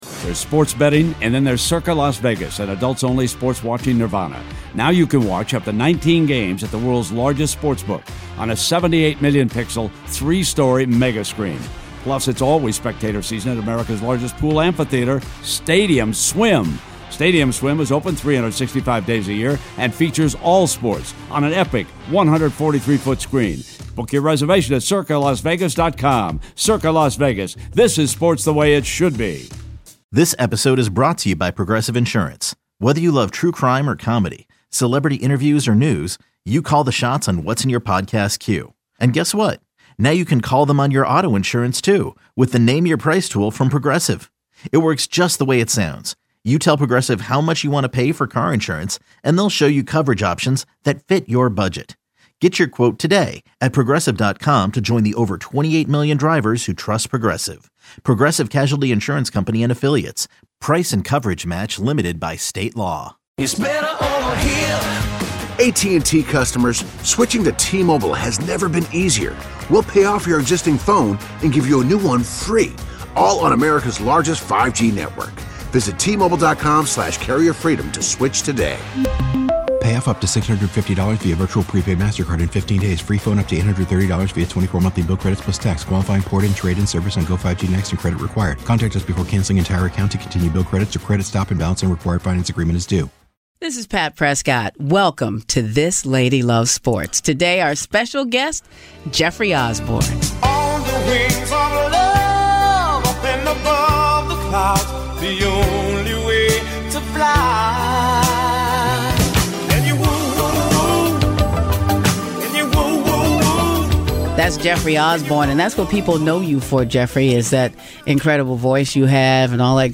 interviews Jeffrey Osborne